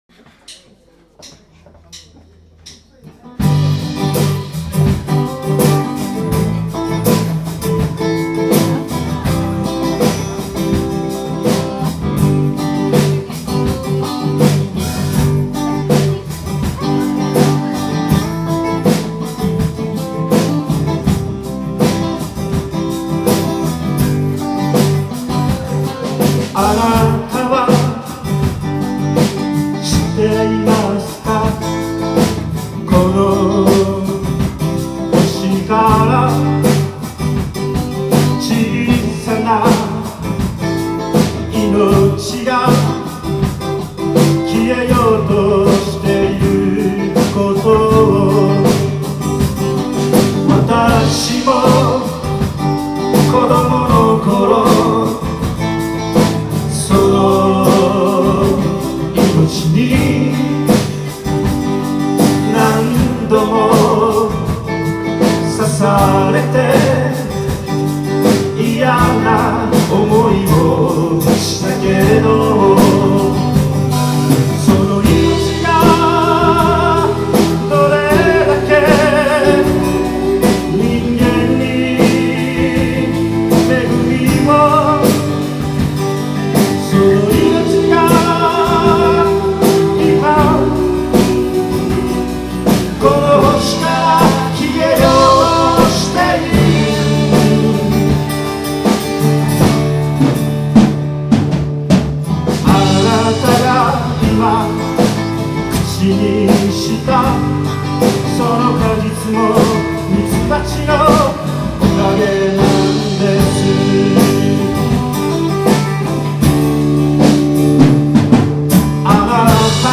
ライブ